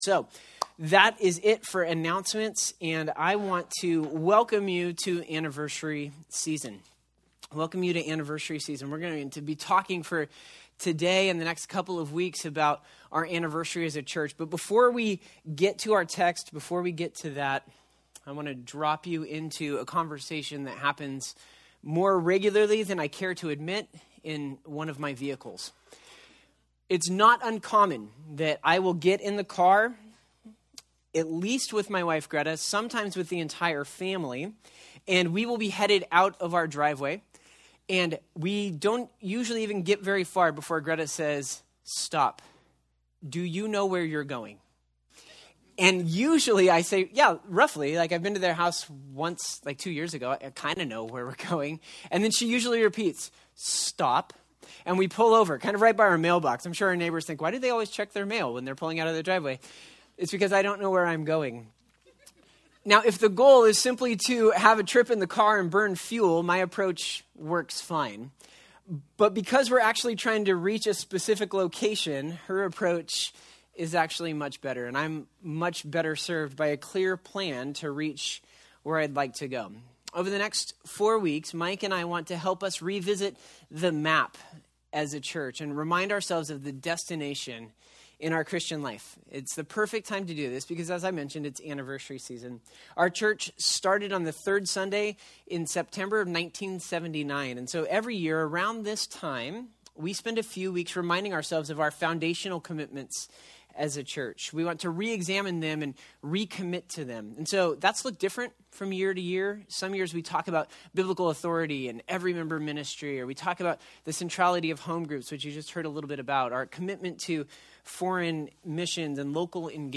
Acts 17:1-15 Service Type: Sunday Topics